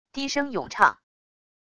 低声咏唱wav音频